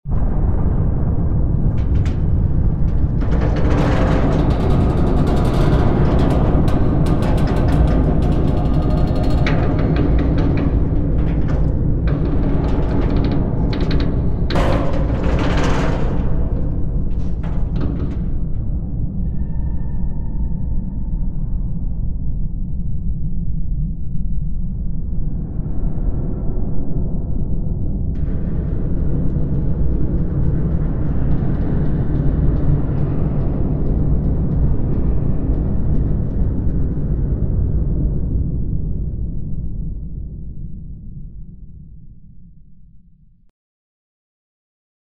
Звук аварии на подводной лодке